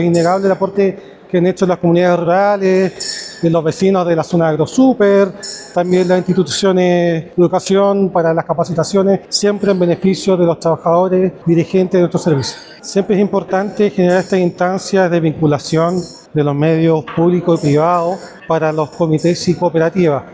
Con la presencia de representantes del sector público y privado, instituciones académicas y directivos de Servicios Sanitarios Rurales (SSR), se llevó a cabo el primer Seminario Regional “Servicios Sanitarios Rurales de O’Higgins: Desafíos y Oportunidades hacia el 2036” en la sede de Inacap Rancagua.